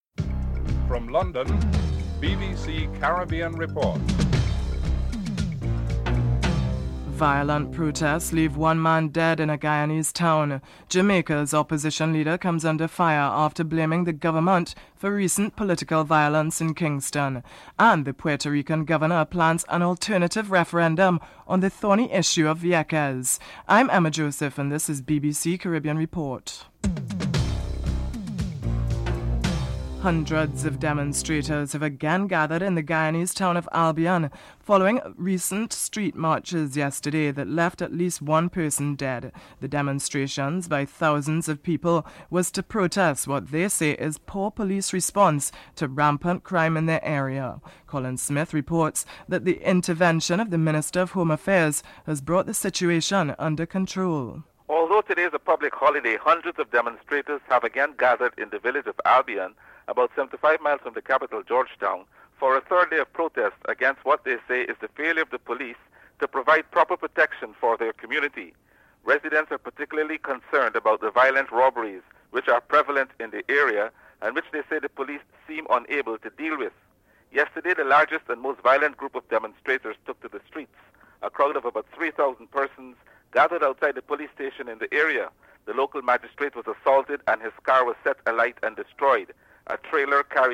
1. Headlines (00:00-00:29)